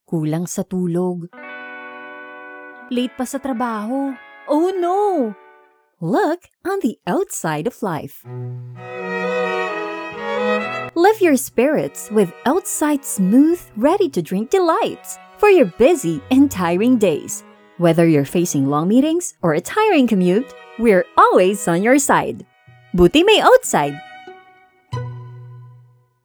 Female
My voice type is deep, authoritative, adult, teenager, and corporate.
Radio Commercials